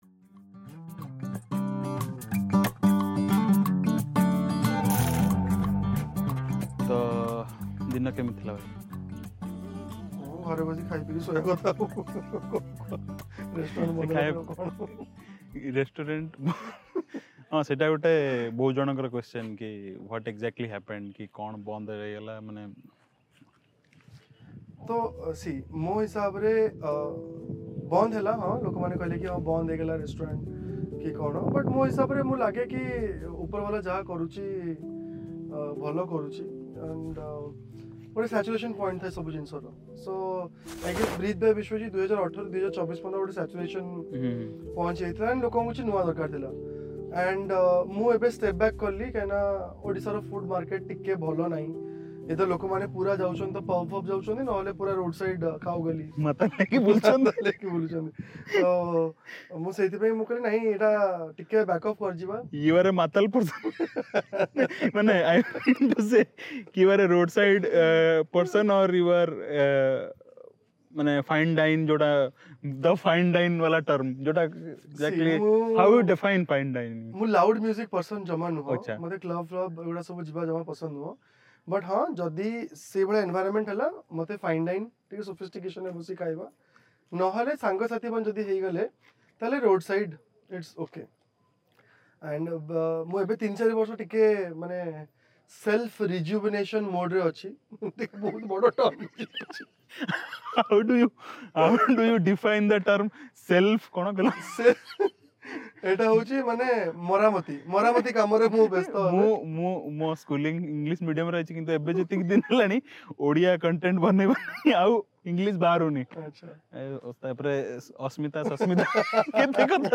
Don’t forget to subscribe for more Fun episodes like this!Khati Your Very Own Odia Talk Show.